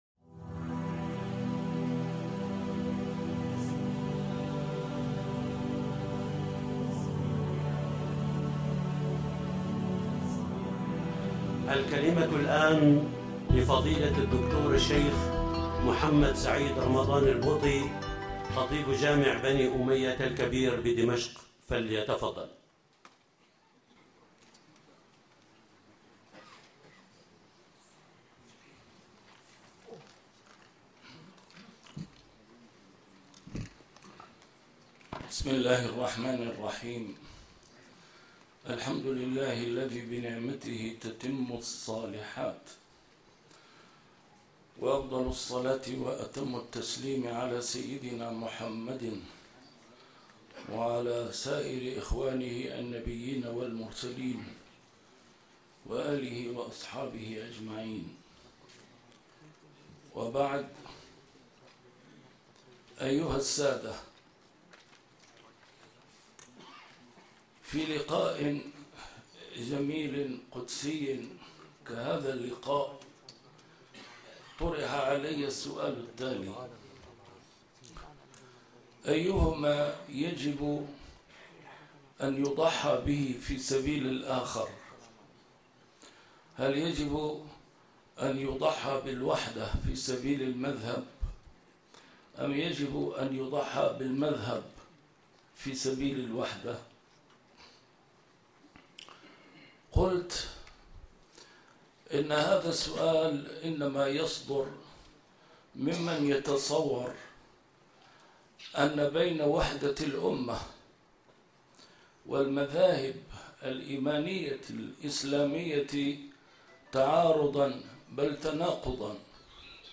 محاضرات متفرقة في مناسبات مختلفة - A MARTYR SCHOLAR: IMAM MUHAMMAD SAEED RAMADAN AL-BOUTI - الدروس العلمية - أيهما يجب أن يضحى به في سبيل الآخر ...